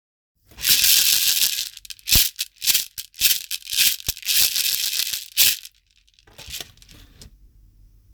種シェケレ(シェイカー) LL
ひょうたんに種をつけたシェケレです。ビーズタイプより音がきつくなく素朴で抜けのよい音色が特徴。
素材： ひょうたん 実 木綿糸